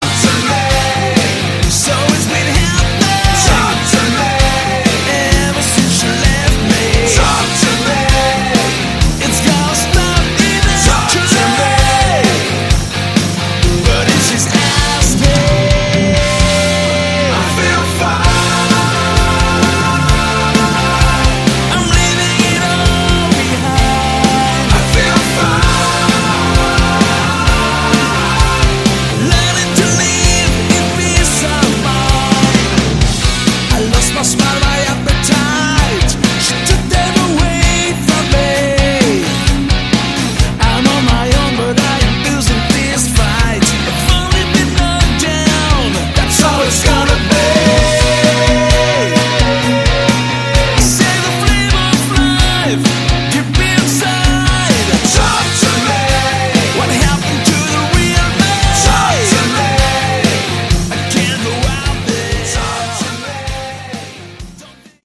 Category: AOR / Melodic Rock
Lead Vocals, Keyboards, Backing Vocals
Drums, Percussion